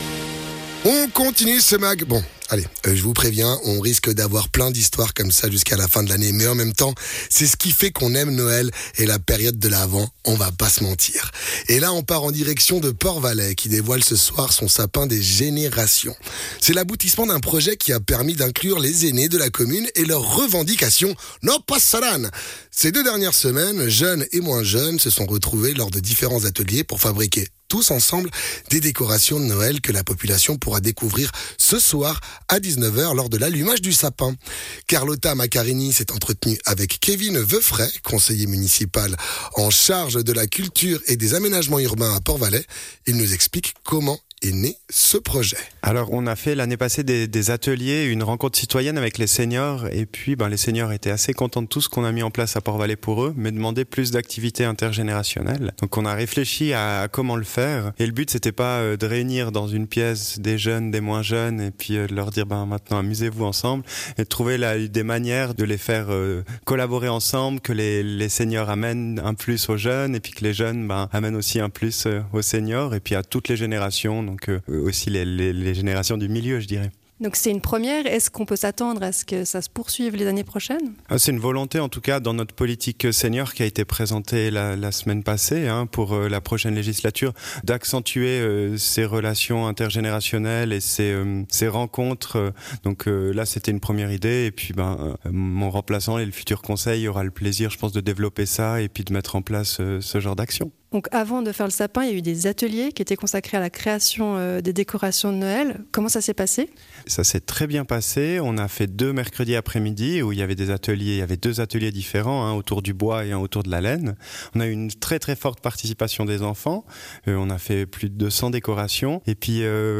Intervenant(e) : Kevin Woeffray, Conseiller communal de la culture et des aménagements urbains, Port-Valais